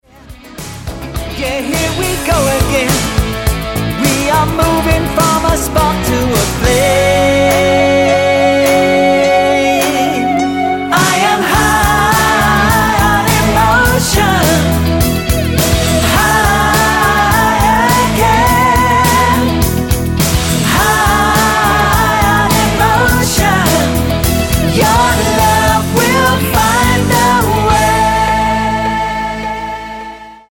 Tonart:Dbm ohne Chor